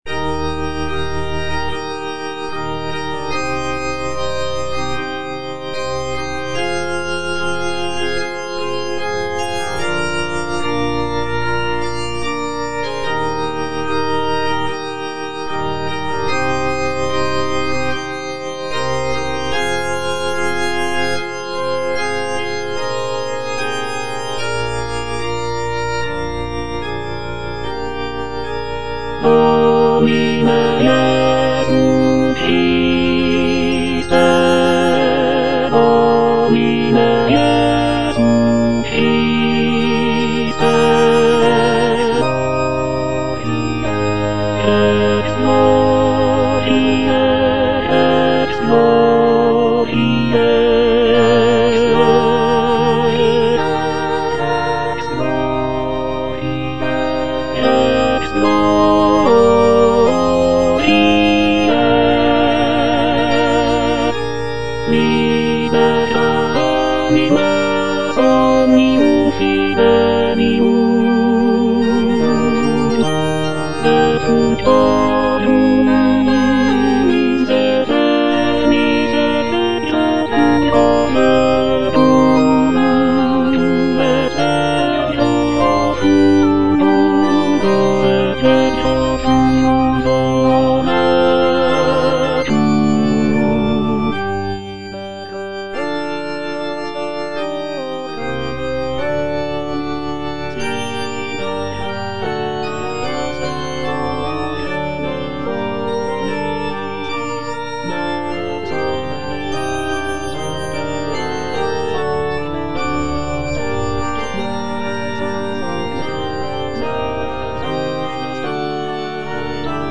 Tenor (Emphasised voice and other voices) Ads stop